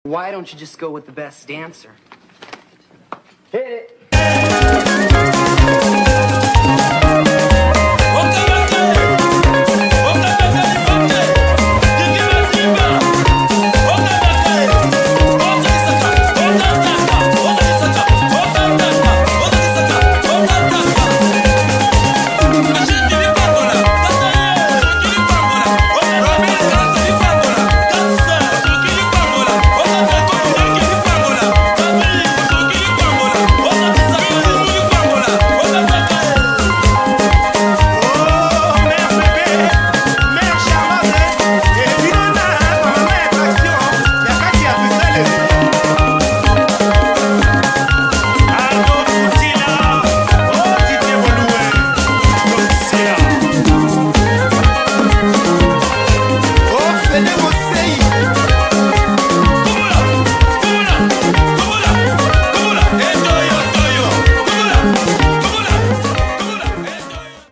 Congolese music